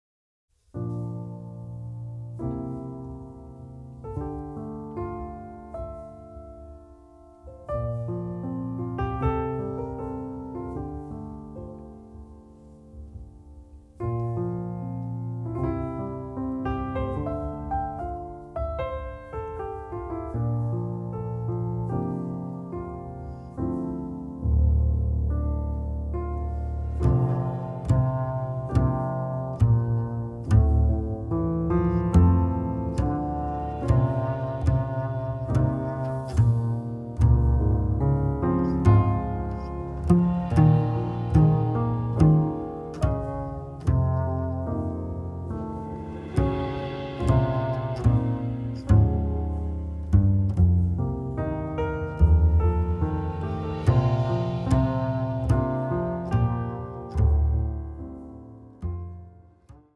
平易な表現を越えつつも、凛としたピアニズムは聴き手と共に広がってゆく。
三位一体のインプロヴィゼーションさえ空を見るように、ただ触れてほしい1枚。